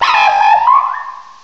cry_not_mienfoo.aif